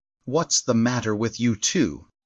vox-cloned-data
Text-to-Speech